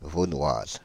Vaunoise (French pronunciation: [vonwaz]
Fr-Paris--Vaunoise.ogg.mp3